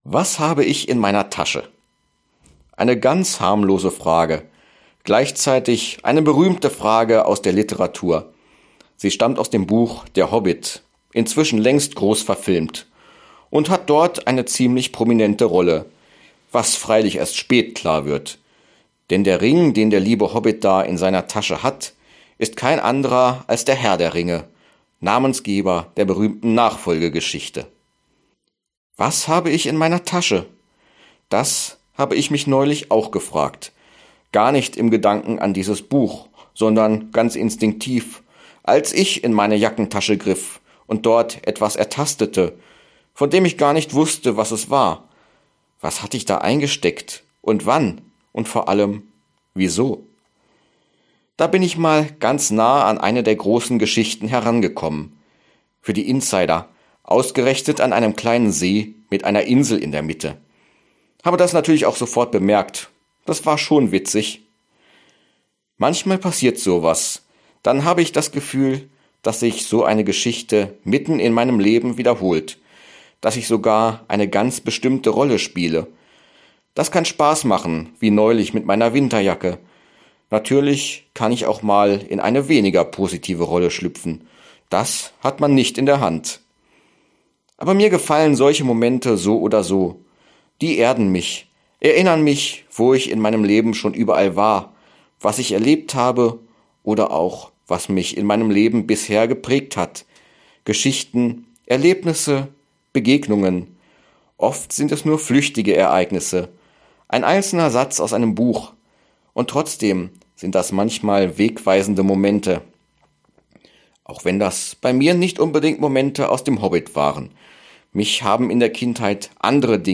Radioandacht vom 19. April